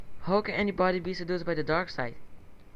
Audio file recording of the dialog of the movie SBTDS at Wikiversity Film School for creating an animatic
Self recorded using home computer